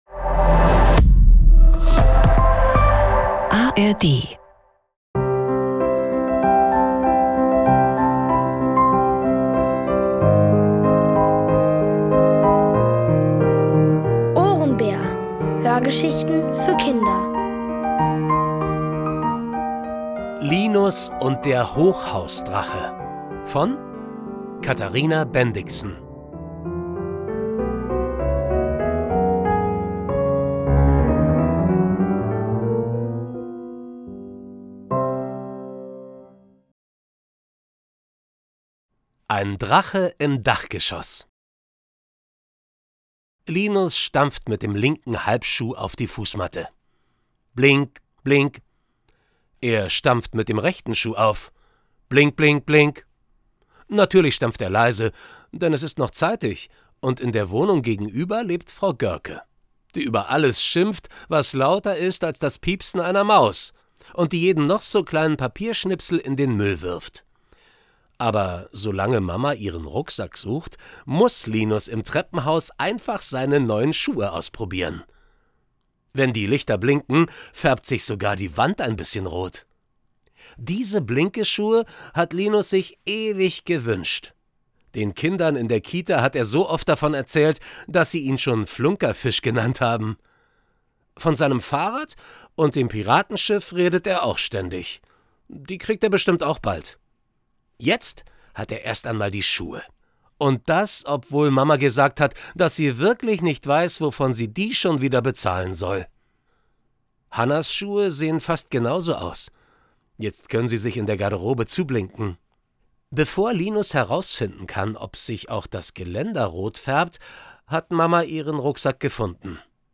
Linus und der Hochhausdrache | Die komplette Hörgeschichte!